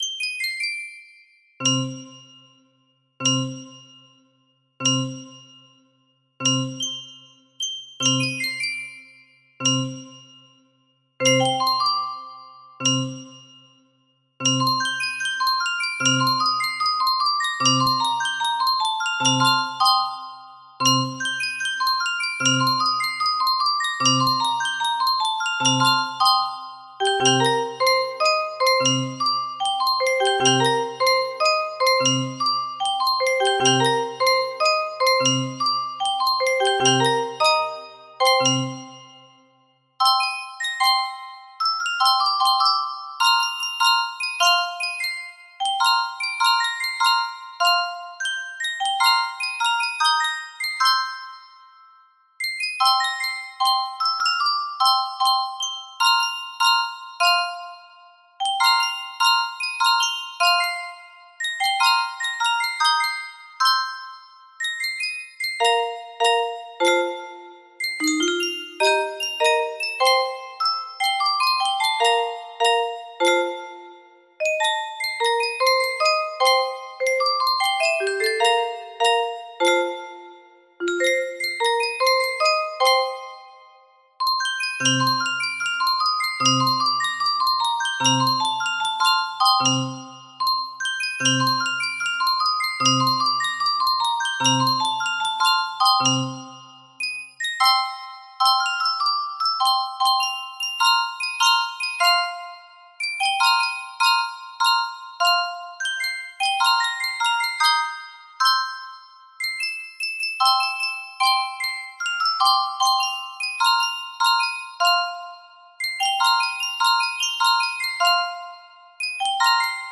Full range 60
very much full ranged solo play version enjoy